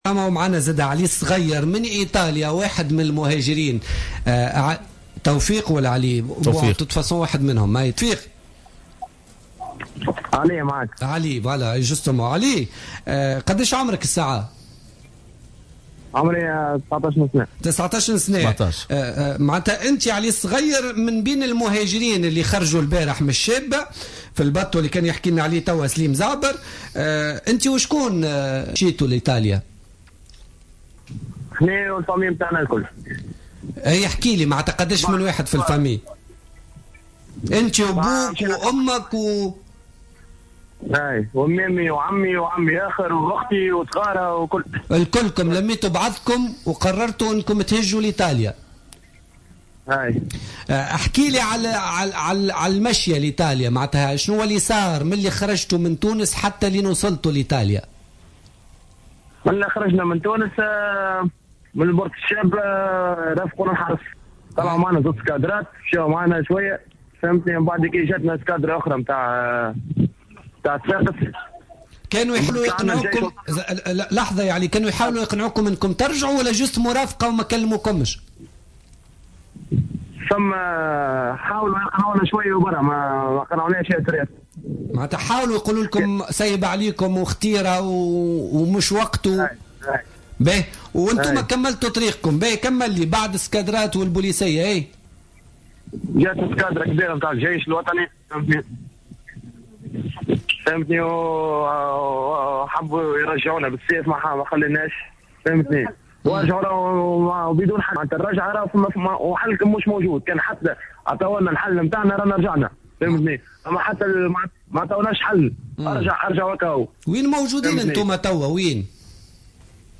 مباشرة من "لمبدوزا"